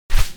Sfx Player Wingflap Sound Effect
sfx-player-wingflap.mp3